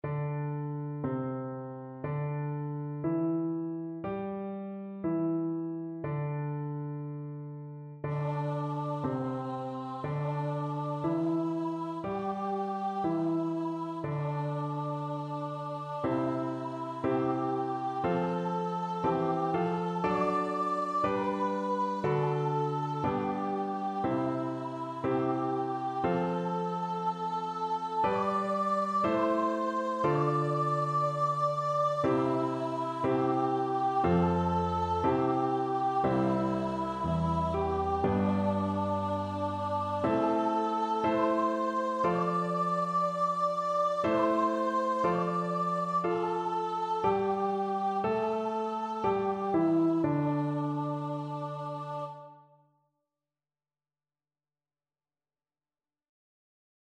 Voice
C major (Sounding Pitch) (View more C major Music for Voice )
Slow
4/4 (View more 4/4 Music)
C5-D6
kimigayo_VOICE.mp3